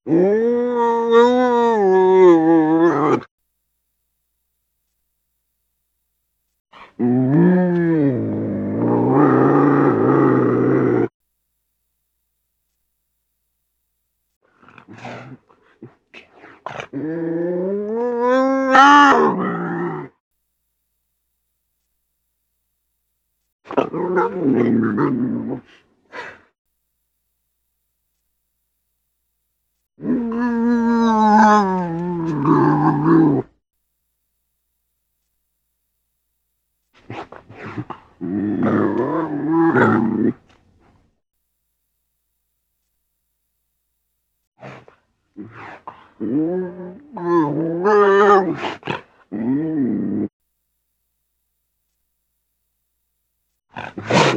🐱 Wildkatze - Wildtiere in Deutschland
Wildkatze-Geraeusche-Wildtiere-in-Europa.wav